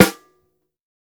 TC2 Snare 19.wav